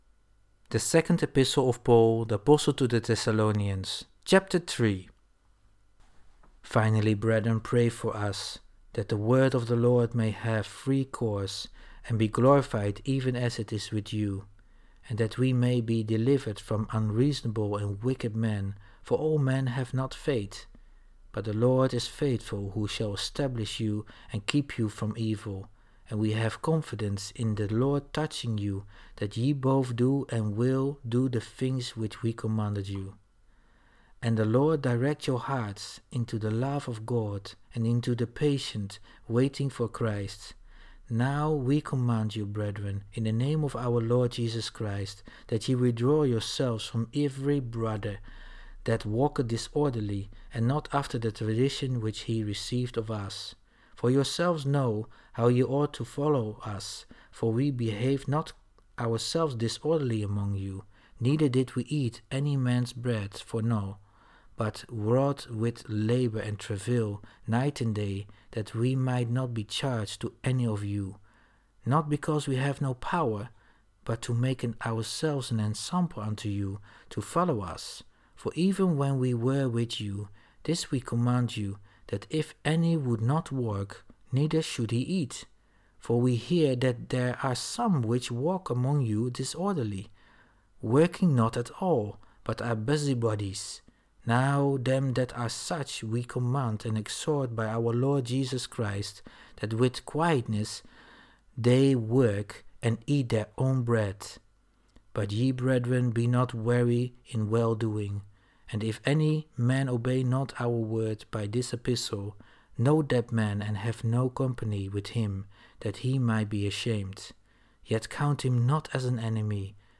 topic: Bible reading
Bible reading. (KJV)